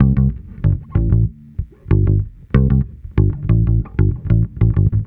Track 13 - Bass 03.wav